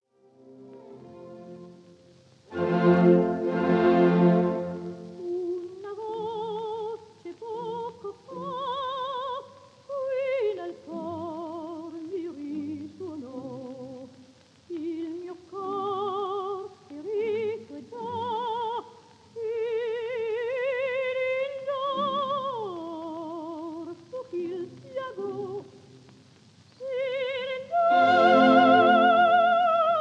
French lyric soprano